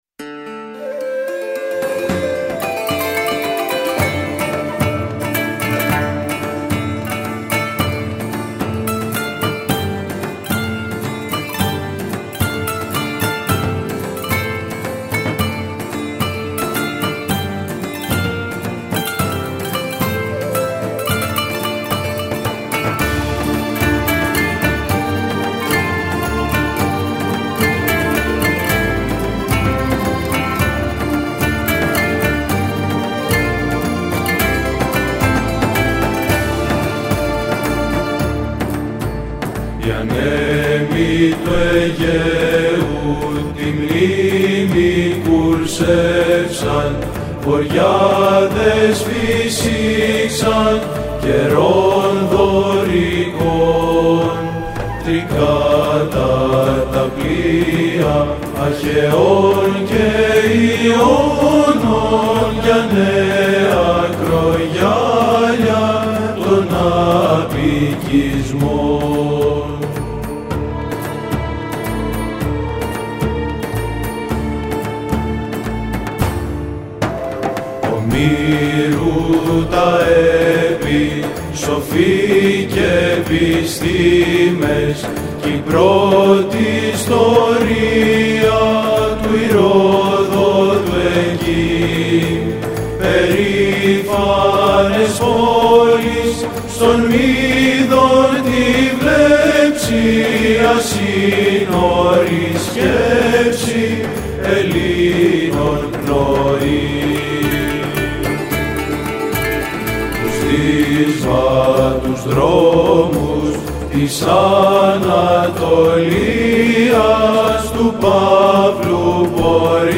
Dmin